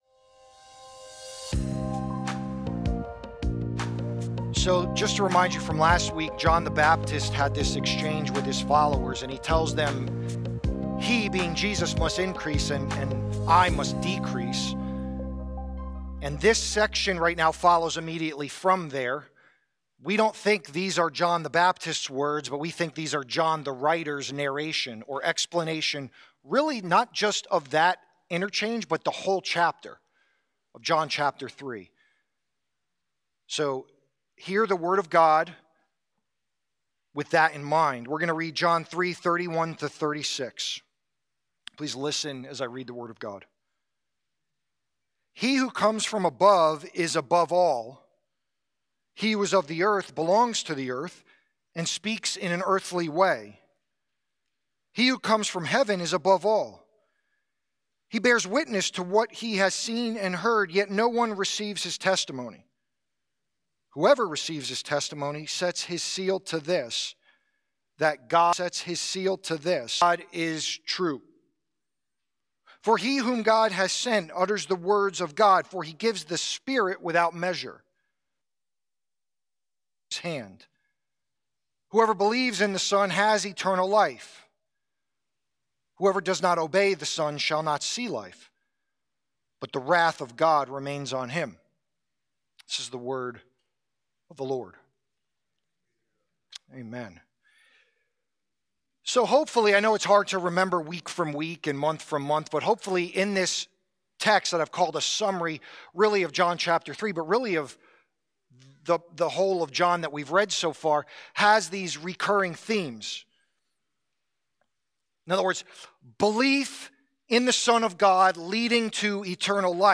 SERMON – Knowing Truth & the Creator-Creature Distinction